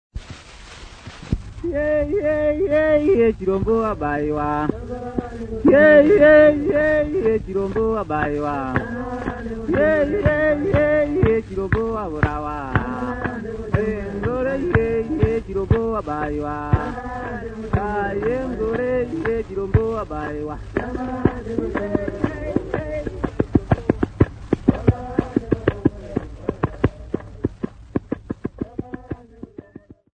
Karanga young men
Folk Music
Field recordings
sound recording-musical
Indigenous music